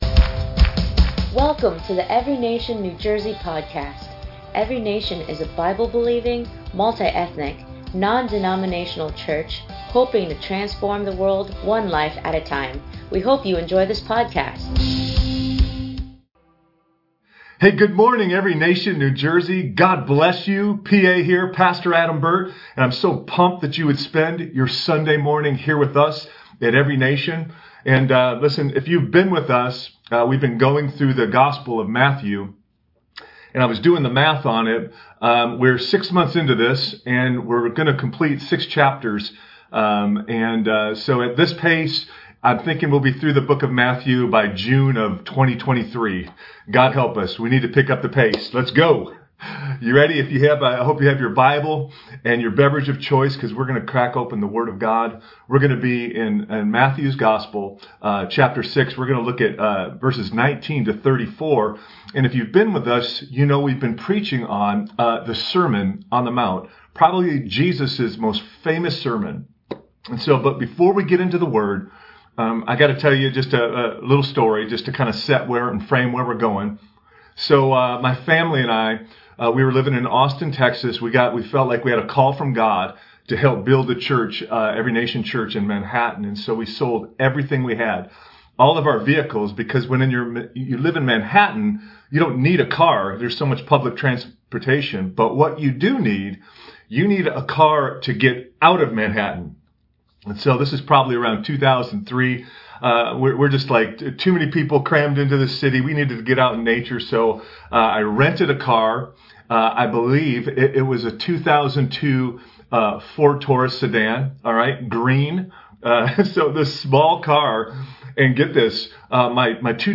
ENCNJ Sermon 9/5/21